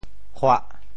“欻”字用潮州话怎么说？
欻 部首拼音 部首 欠 总笔划 12 部外笔划 8 普通话 xū chuā 潮州发音 潮州 huh4 白 中文解释 欻〈象〉 踩踏声 [the sound of tramping] 形容急促的声响。